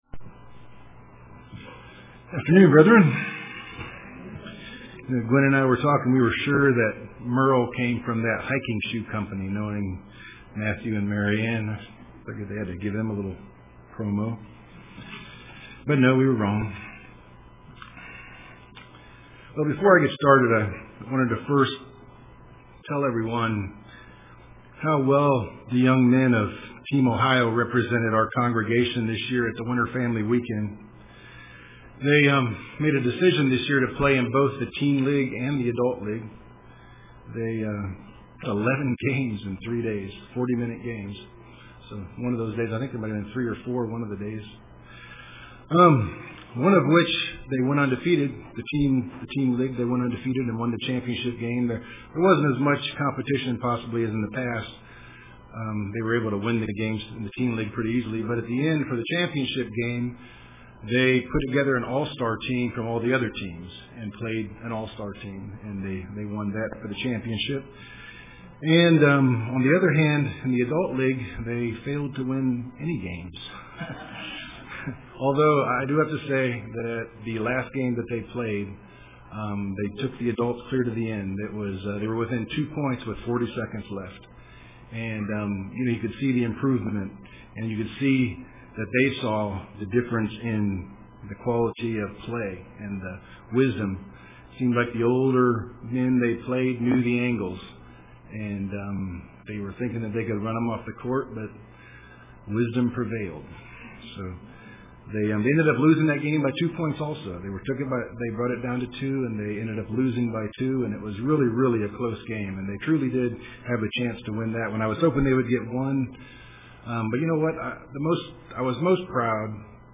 Print Brothers-1500 Years Apart UCG Sermon Studying the bible?